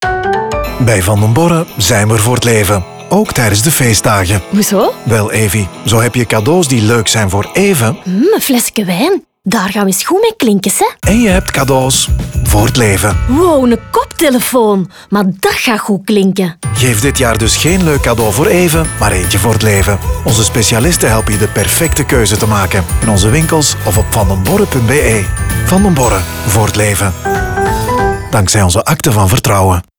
Radio
Radio Production: Sonhouse